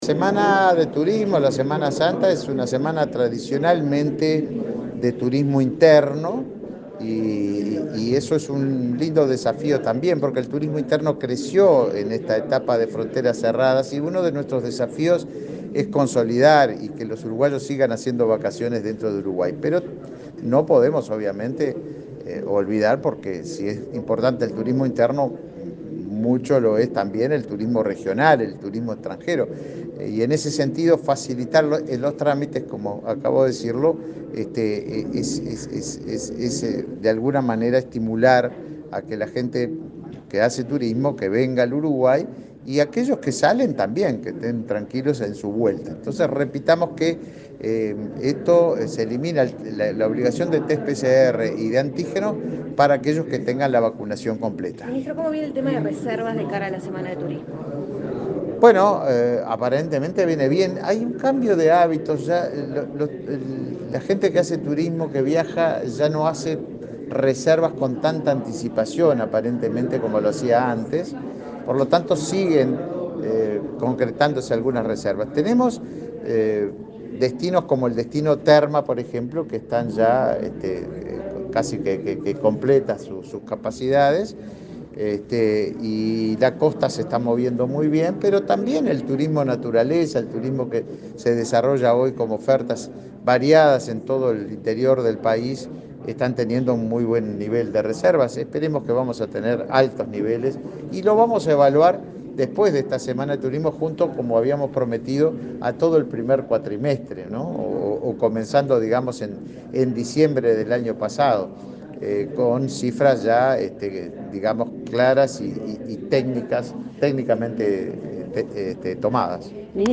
Declaraciones a la prensa del ministro de Turismo, Tabaré Viera
Luego, dialogó con la prensa.